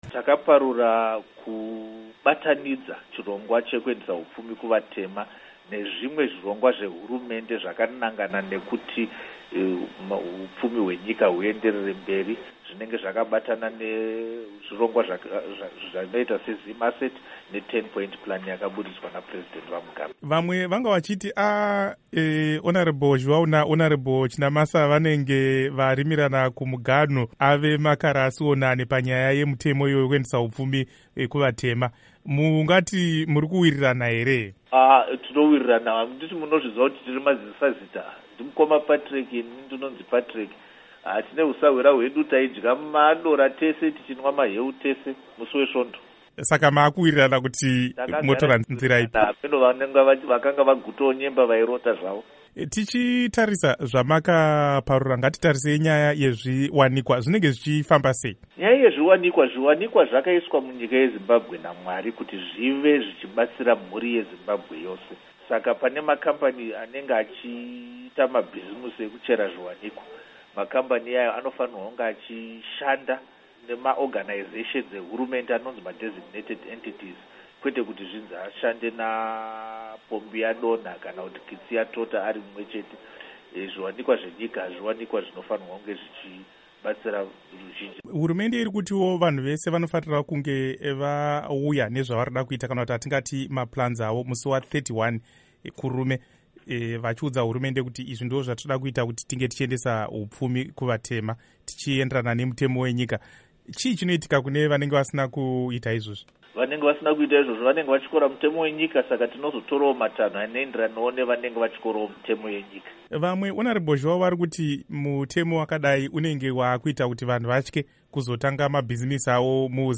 Hurukuro naVaPatrick Zhuwawo